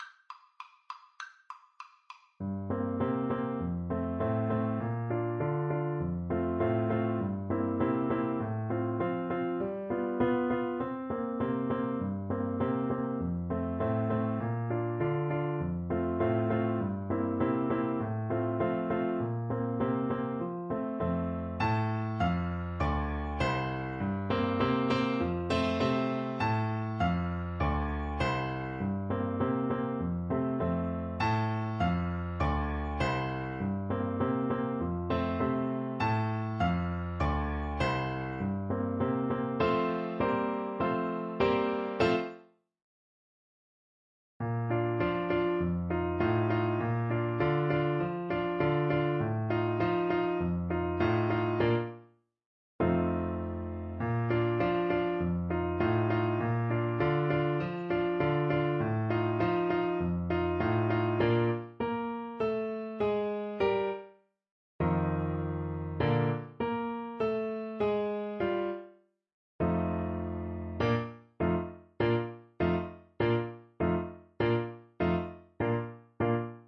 Play (or use space bar on your keyboard) Pause Music Playalong - Piano Accompaniment Playalong Band Accompaniment not yet available transpose reset tempo print settings full screen
Bassoon
4/4 (View more 4/4 Music)
Allegro =200 (View more music marked Allegro)
F major (Sounding Pitch) (View more F major Music for Bassoon )
Classical (View more Classical Bassoon Music)